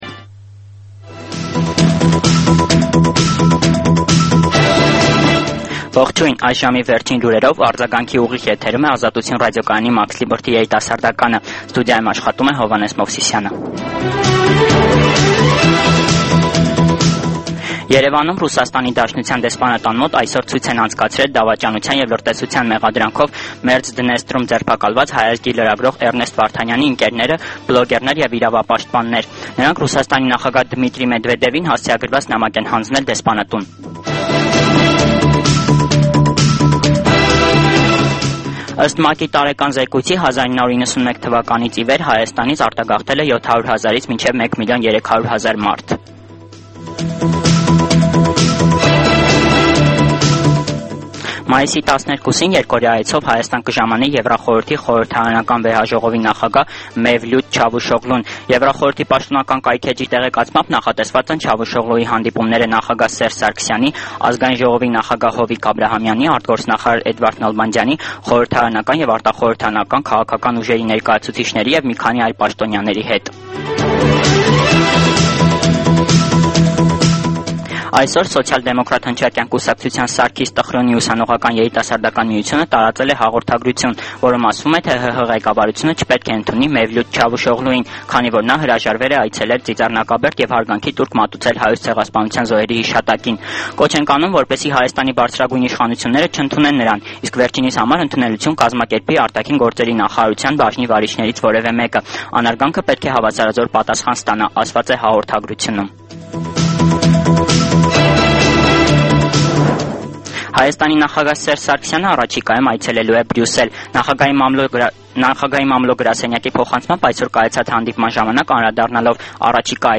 Լուրեր
Տեղական եւ միջազգային վերջին լուրերը ուղիղ եթերում: